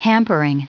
Prononciation du mot hampering en anglais (fichier audio)
Prononciation du mot : hampering